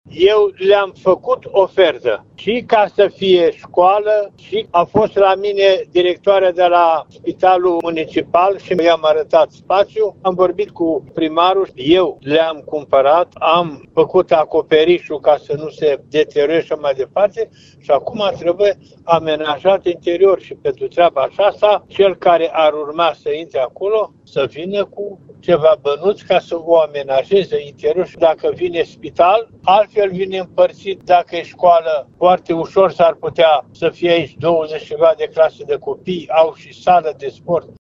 Clădirea aparține Mitropoliei Banatului, iar ÎPS Ioan a declarat pentru Radio Timișoara că viitorului chiriaș îi revine amenajarea interioară a spațiului, urmând ca investiția să fie scăzută din prețul chiriei.
02-mitropolit-liceu-arte.mp3